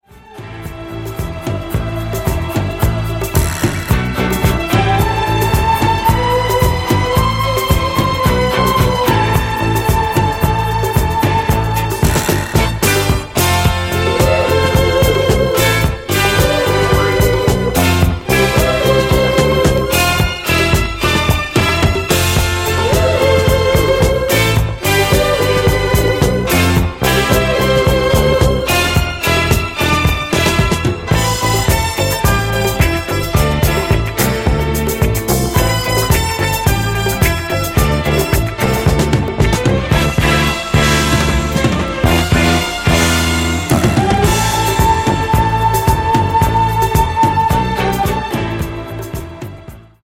Genere:   Disco | Funk | Sunshine Sound